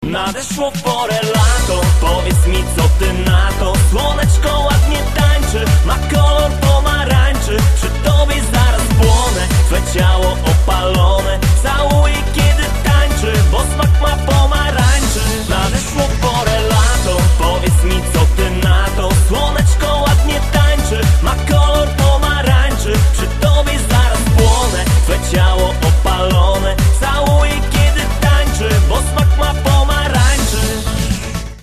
Kategorie Disco Polo